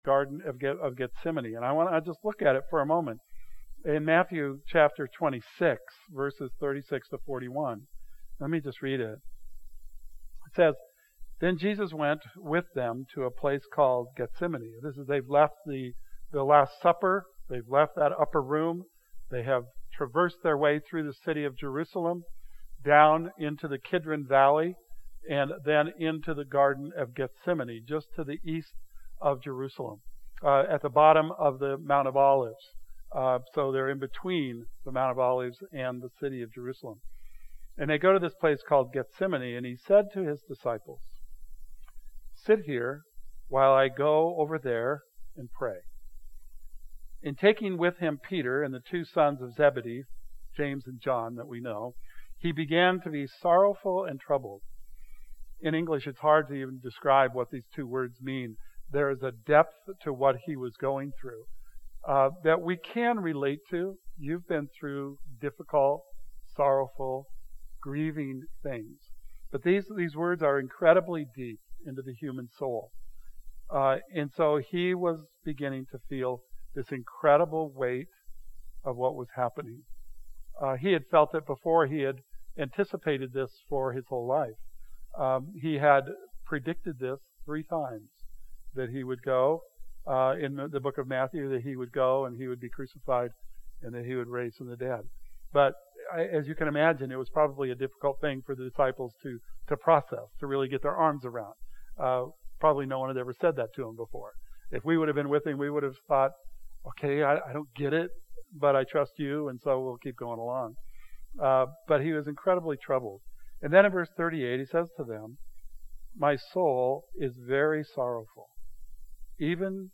Join the Good Friday service. We'll be taking communion, so go ahead and grab the elements needed for that if you wish to participate.